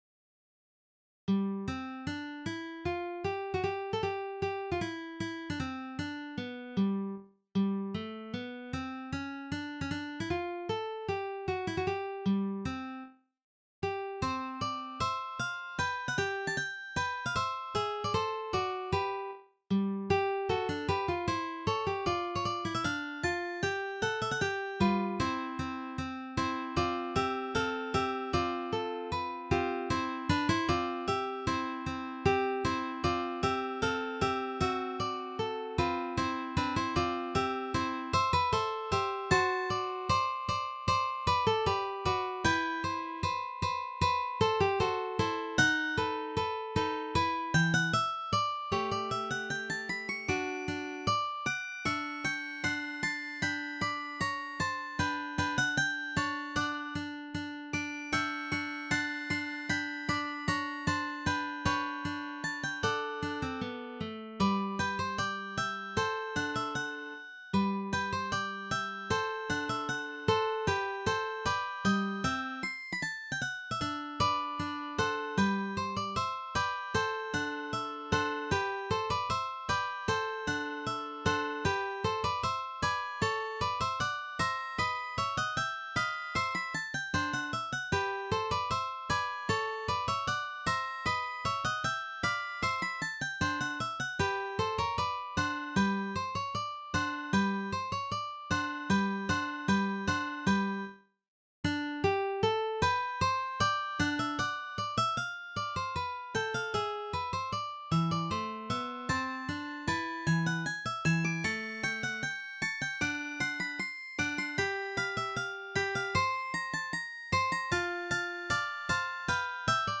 arrangement for 3 guitars